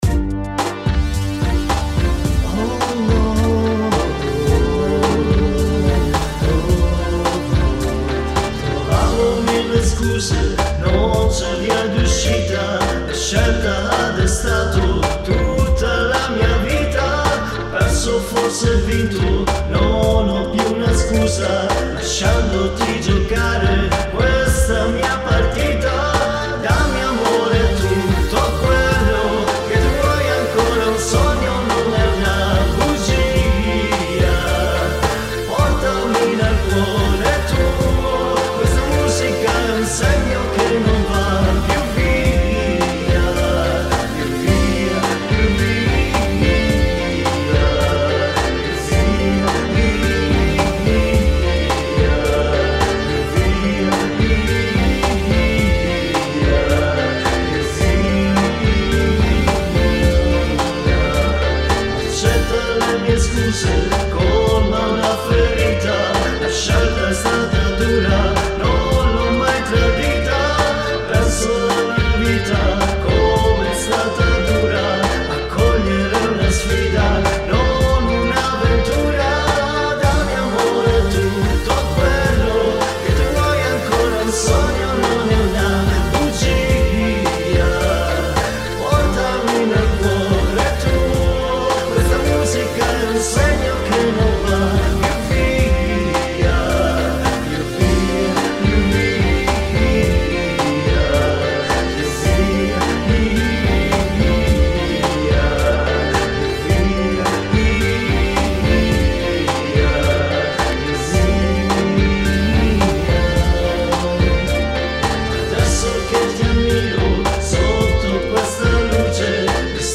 Luogo esecuzioneSassuolo
GenerePop / Musica Leggera